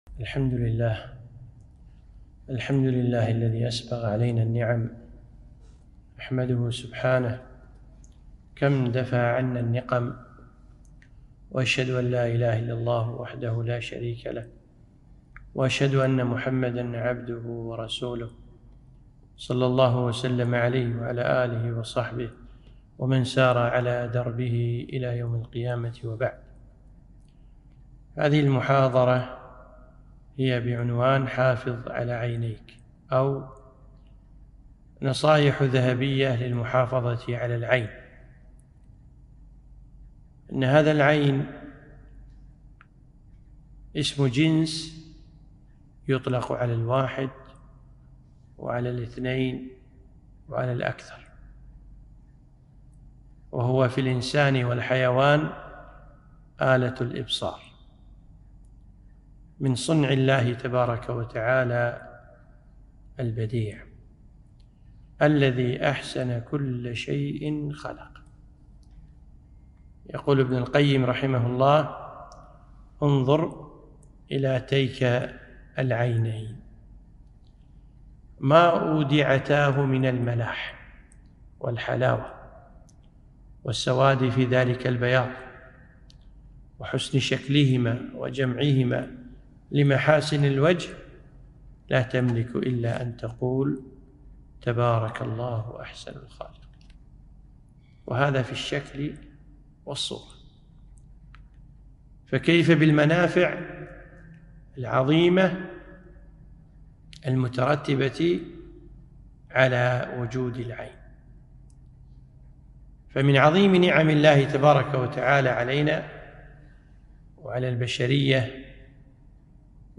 محاضرة - حافظ على عينيك